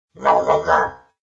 Skel_COG_VO_statement.ogg